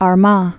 (är-mä, är)